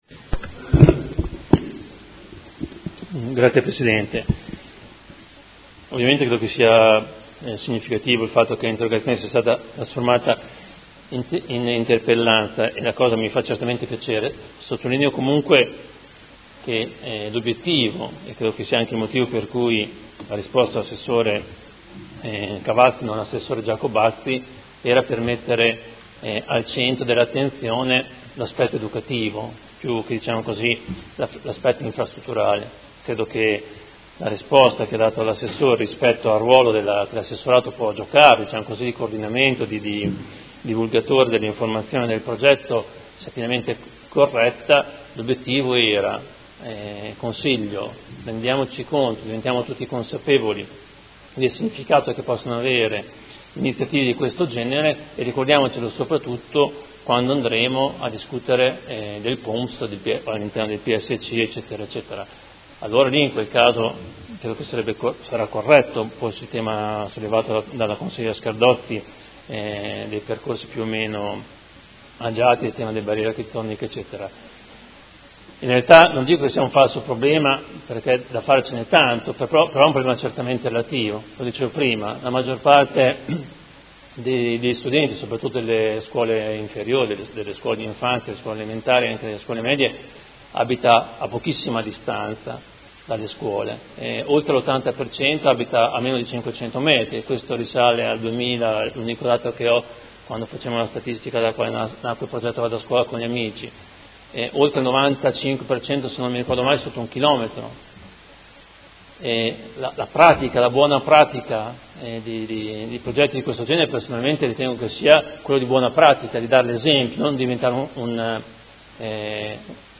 Seduta del 28/04/2016. Interrogazione del Consigliere Poggi (P.D.) avente per oggetto: Promozione della mobilità sostenibile nei percorsi “casa-scuola”.
Audio Consiglio Comunale